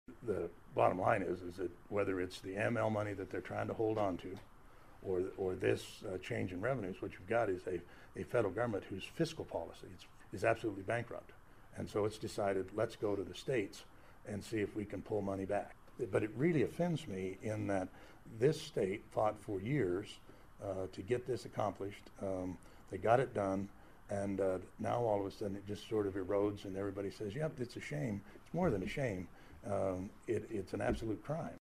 During today’s news conference Freudenthal also responded to questions on the recent announcement that the federal government will reduce the share of federal mineral royalty distributions to the states from 50 percent to 48 percent.